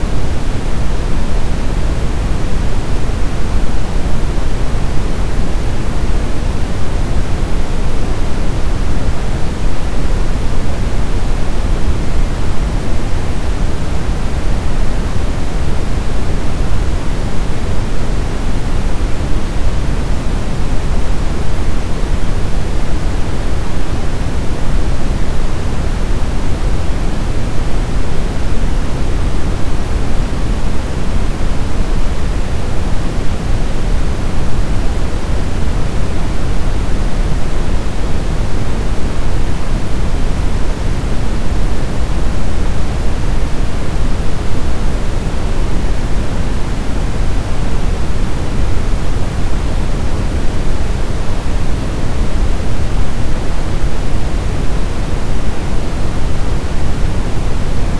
Oulun alueella on viime aikoina havaittu omituista radioliikennettä.